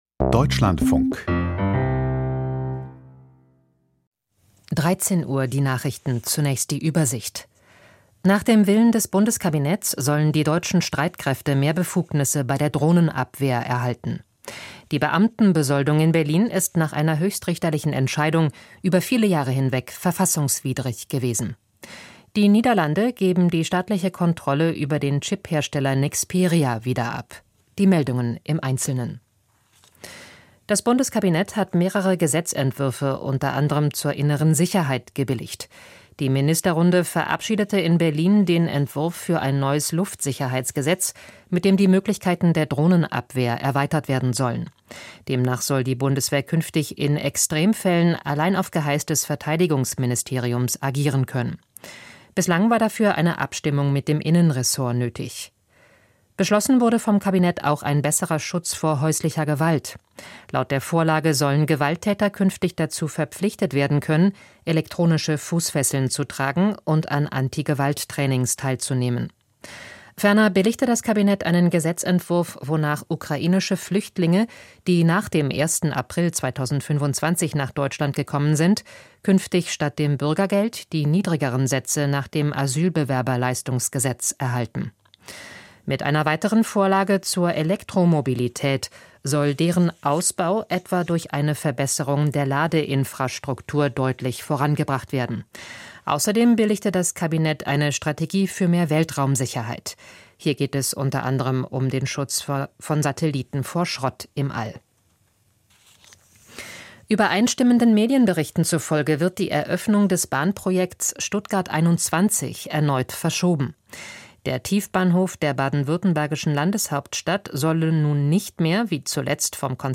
Die Nachrichten vom 19.11.2025, 13:00 Uhr